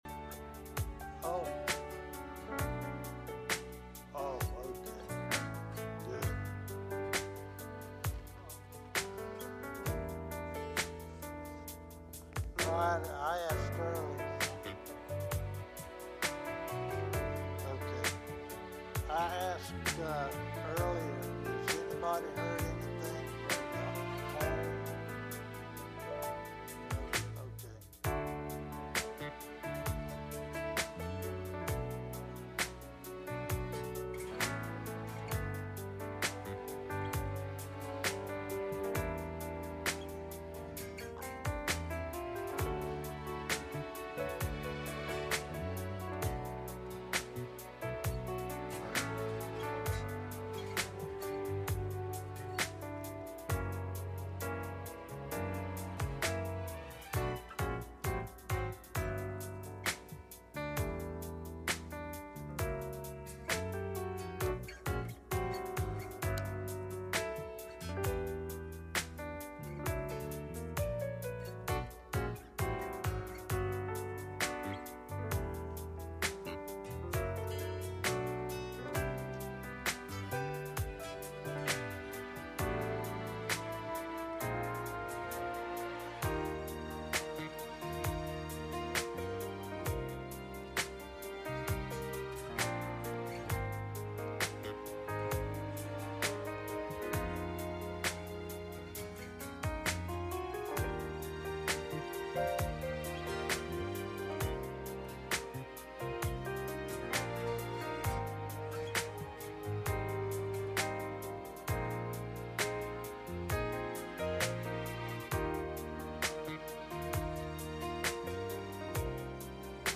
Wednesday Night Service
Message Service Type: Midweek Meeting https